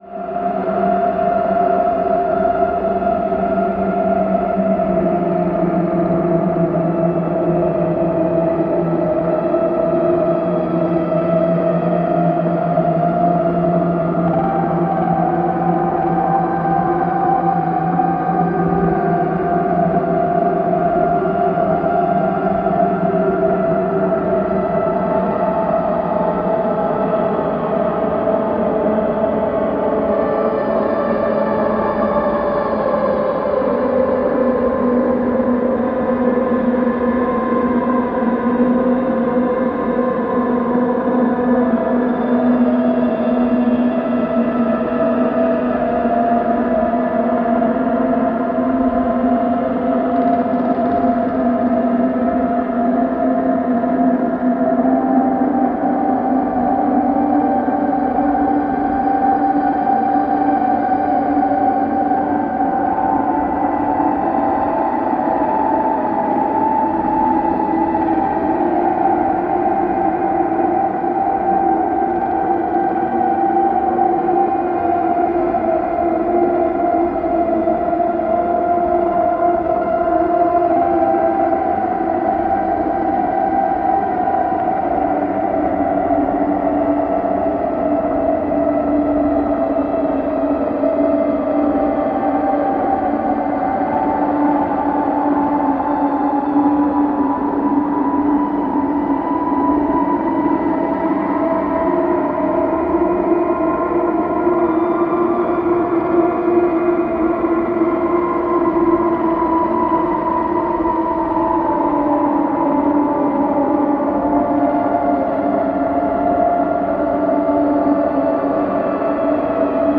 25分超えの長編腐食ドローン2作品を収録した終始ズブズブなテクスチャーが続く一枚。